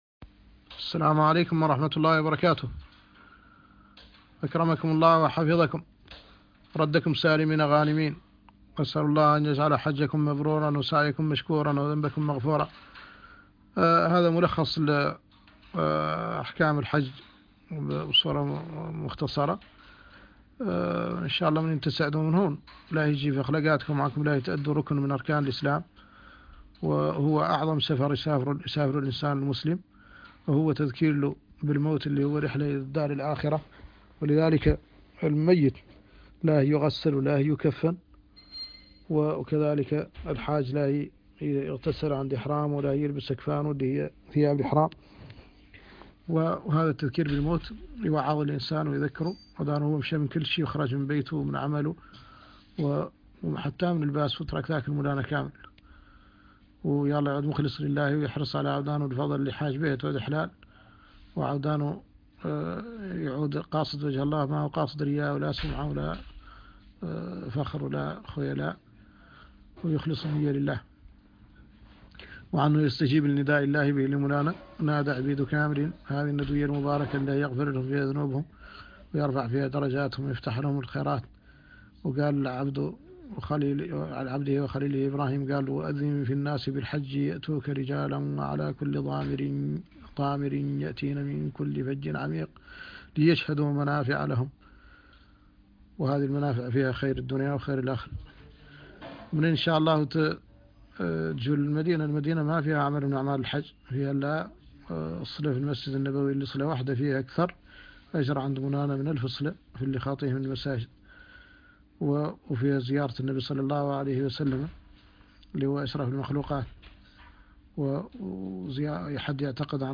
ملخص أحكام الحج بالحسانية - الشيخ محمد الحسن ولد الددو الشنقيطي